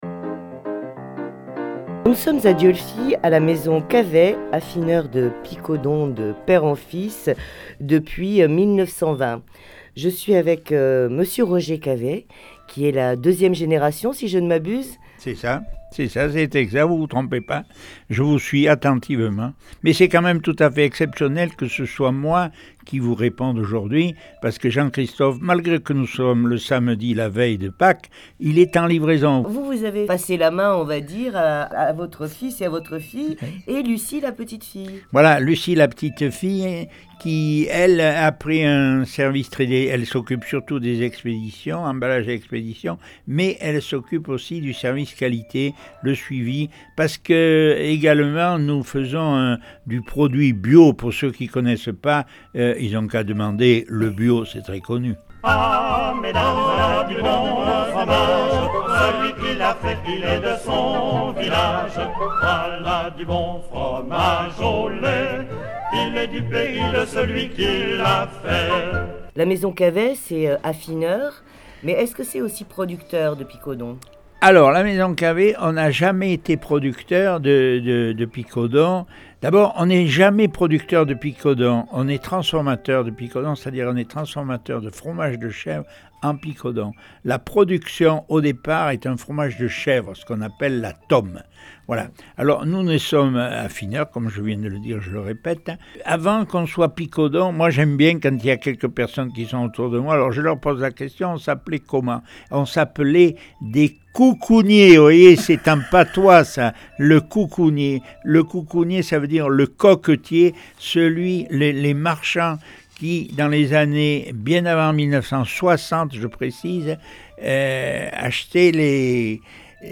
12 juin 2018 16:23 | Interview, reportage